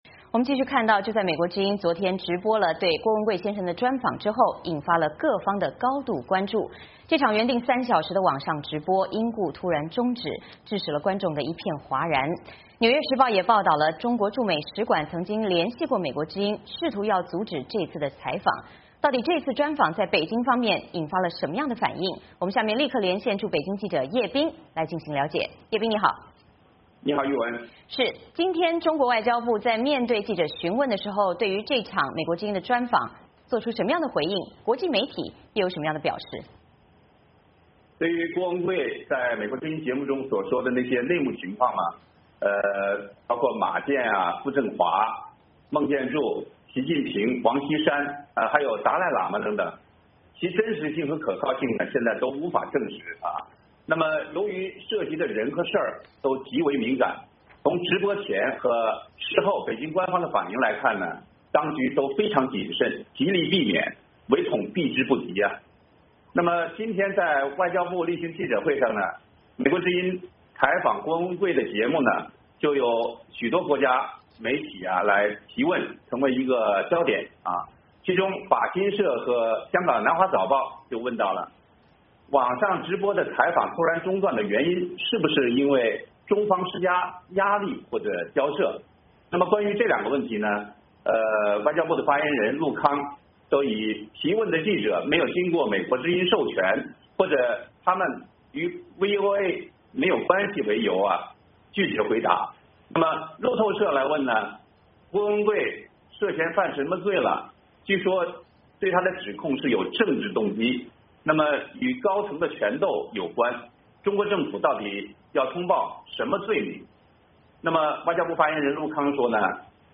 VOA连线：美国之音专访郭文贵，中国如何反应？